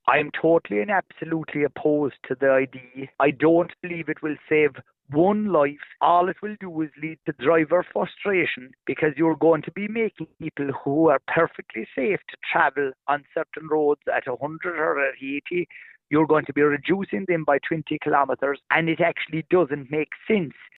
However, Independent TD Michael Healy Rae, doesn’t believe the initiative will save lives: